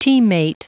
Prononciation du mot : teammate
teammate.wav